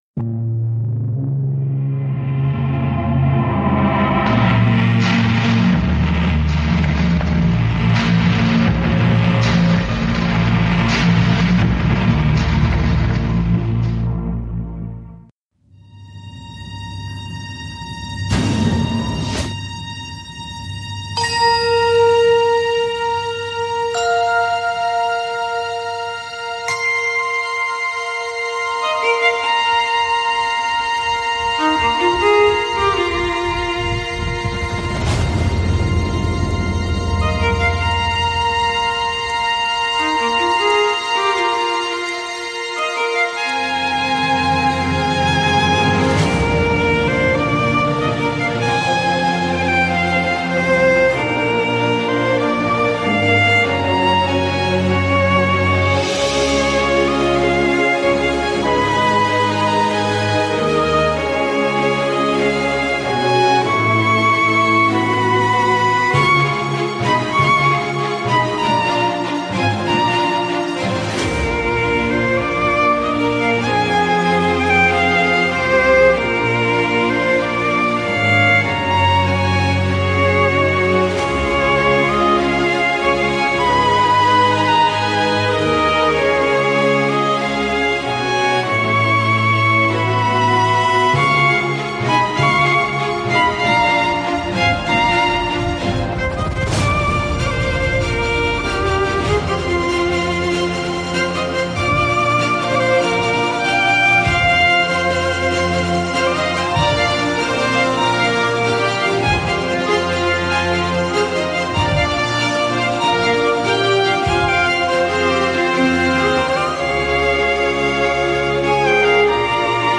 Wir haben uns etwas spontan auf dem 38. Chaos Communication Congress zusammen gefunden und über Lower Decks gesprochen. Dabei bleibt nicht aus über die wirre Distributions Form und Kommunikation von Paramount zu reden, aber wir reden vor allem über unsere Highlights.